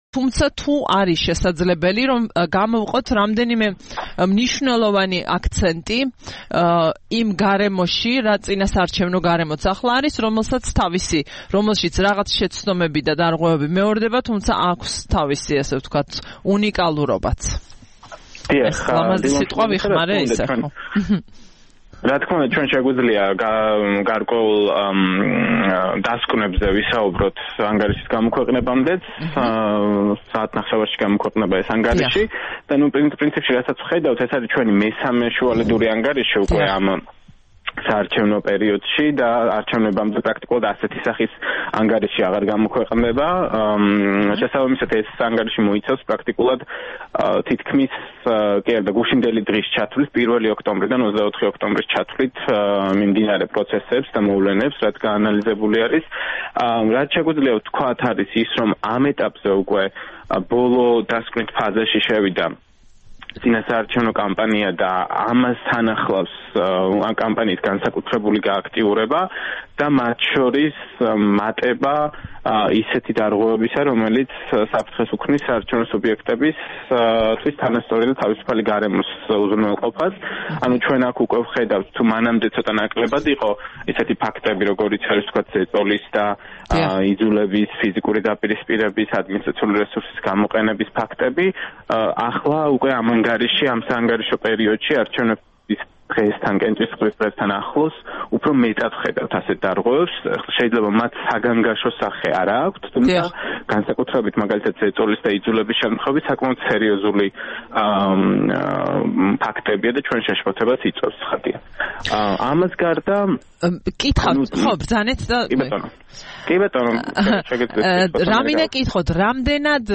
ინტერვიუში